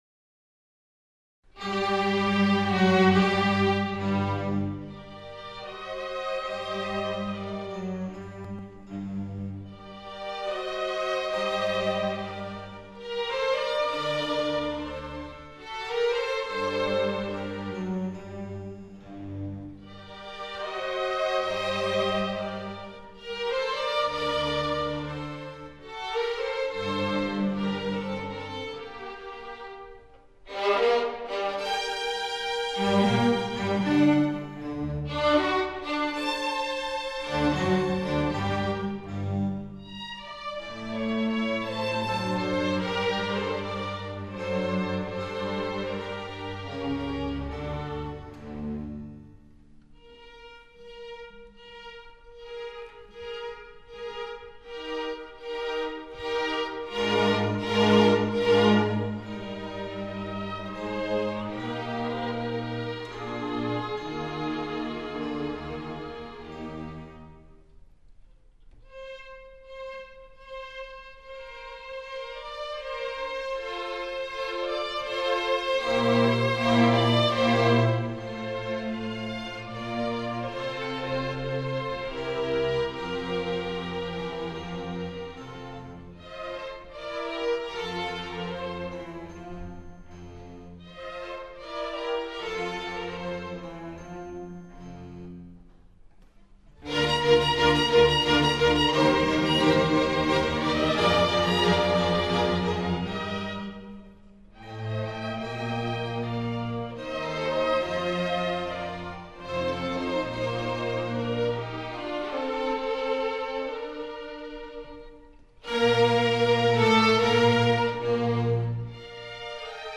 - Nahráno živě v sále Moravské filharmonie Reduta (Ústřední kolo národní soutěže ZUŠ - Olomouc 2003) Komorní orchestr